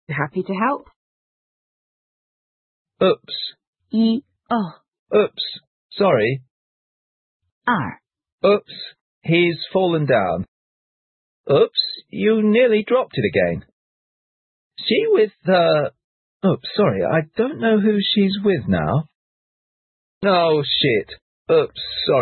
cops是oops a daisy 的省略形式。有人也说}wu:ps},写作 whoop。